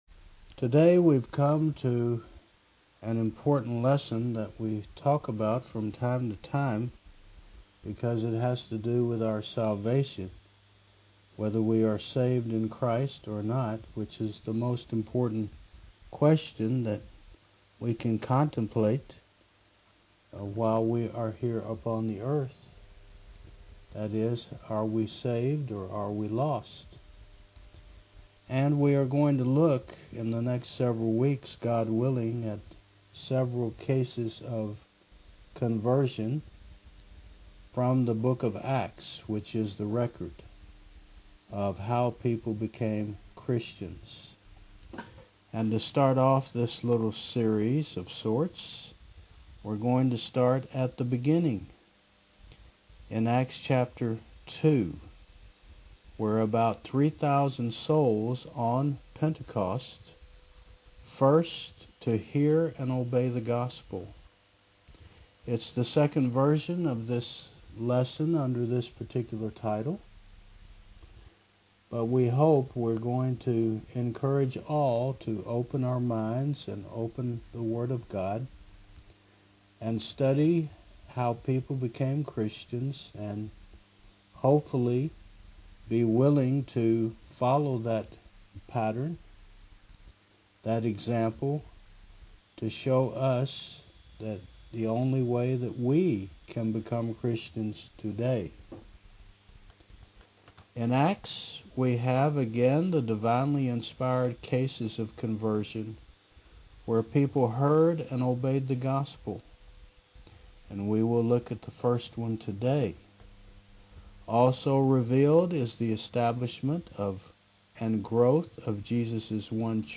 Service Type: Mon. 9 AM